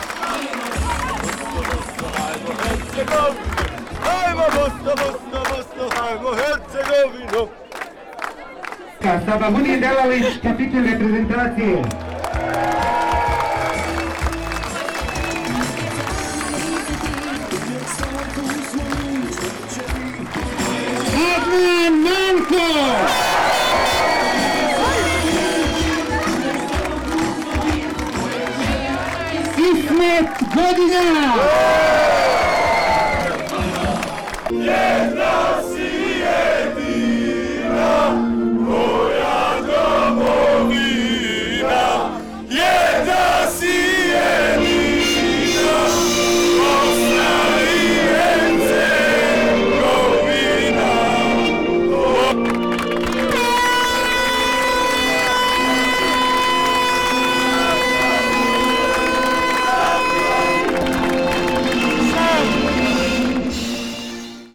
Atmosfera sa dočeka